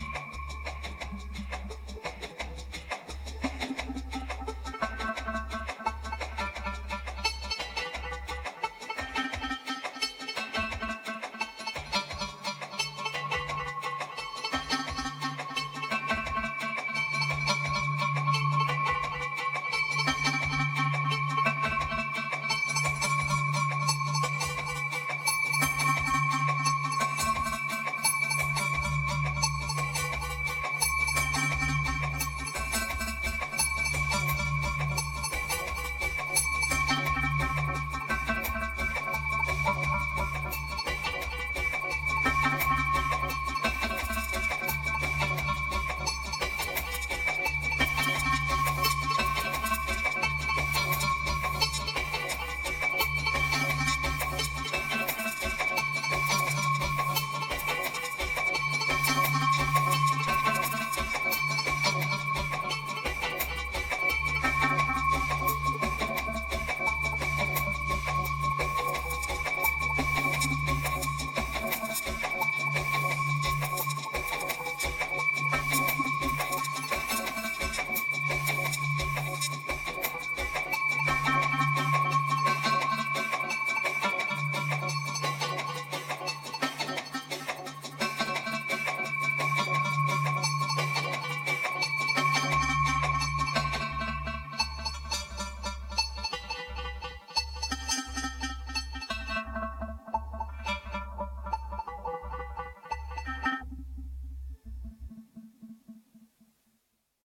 2279📈 - 68%🤔 - 86BPM🔊 - 2017-04-01📅 - 249🌟